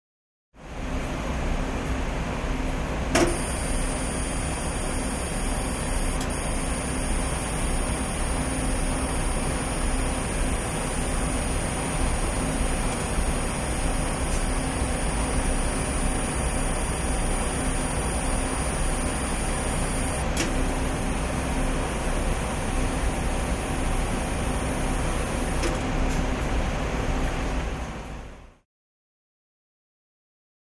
Lab Ambience; Test Machinery 1; Fan / Motor, Mechanical Clanks, Buzzing ( Cicada-like ); Close Perspective. Hospital, Lab.